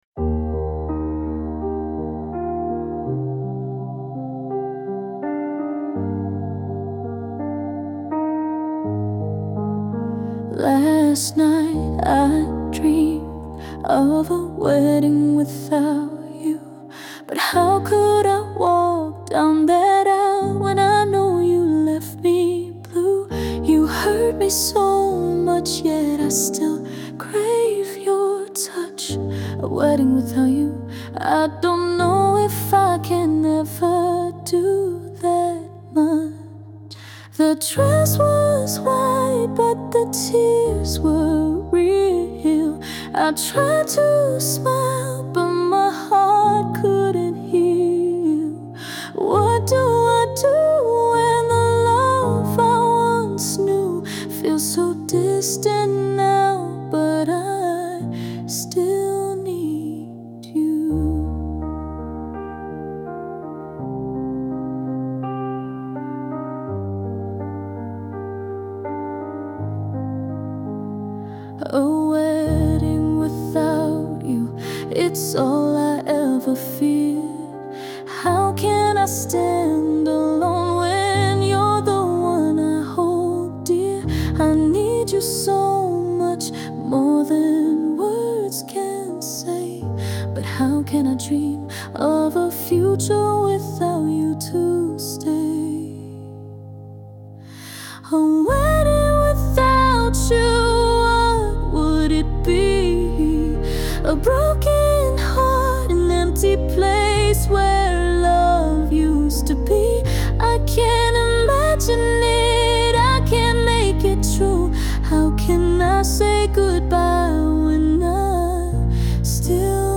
Genre:                   POP / Ballad